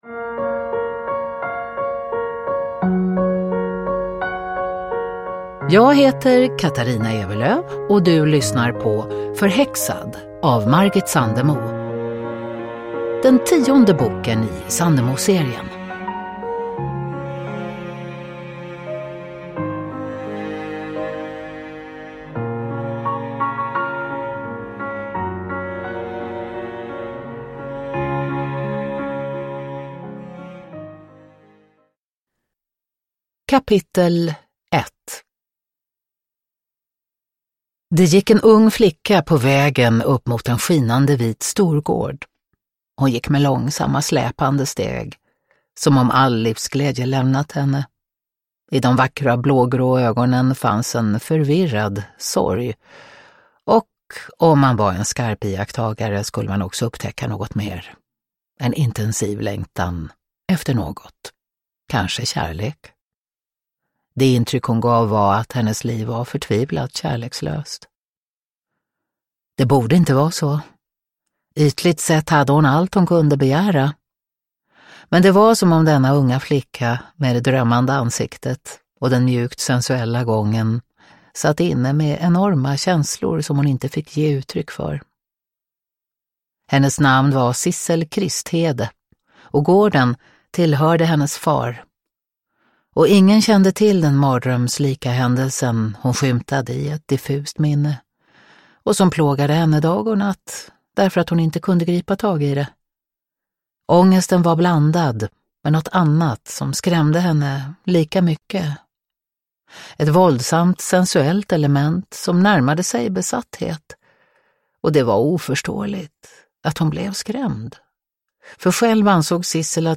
Förhäxad – Ljudbok – Laddas ner
Uppläsare: Katarina Ewerlöf